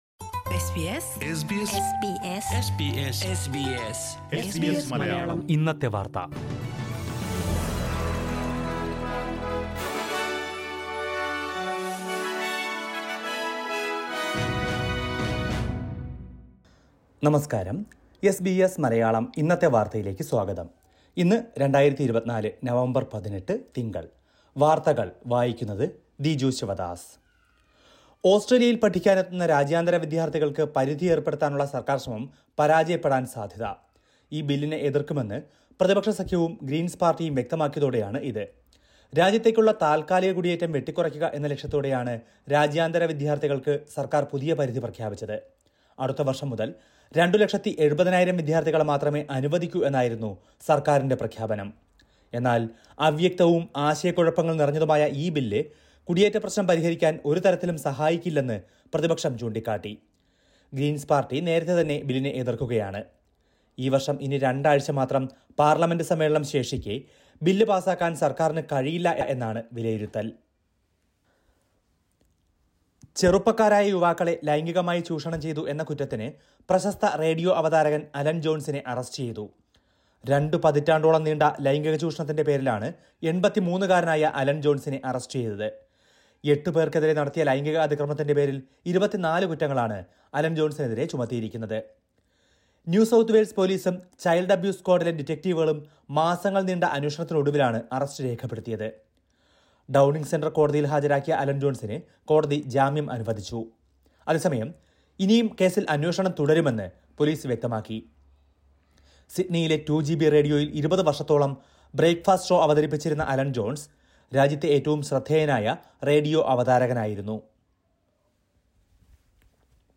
2024 നവംബർ 18ലെ ഓസ്ട്രേലിയയിലെ ഏറ്റവും പ്രധാന വാർത്തകൾ കേൾക്കാം..